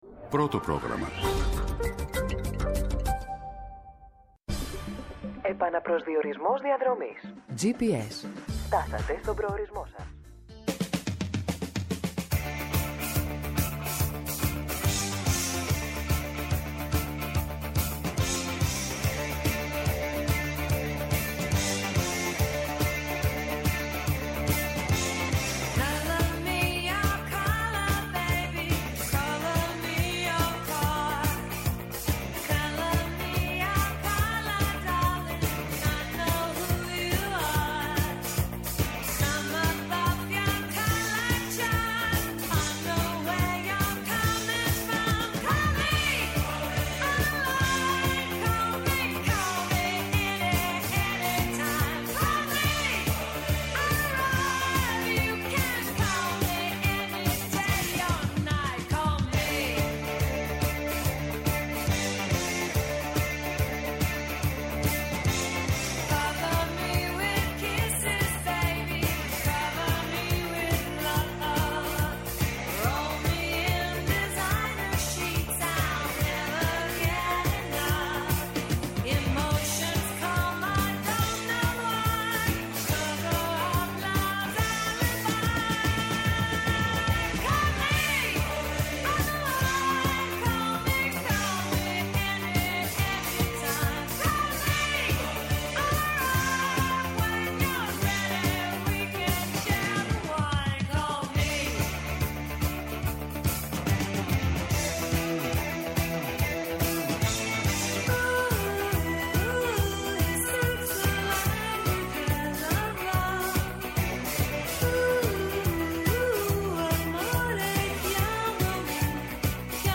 Καλεσμένοι σήμερα : -Η Νίκη Κεραμέως, υπουργός Εσωτερικών.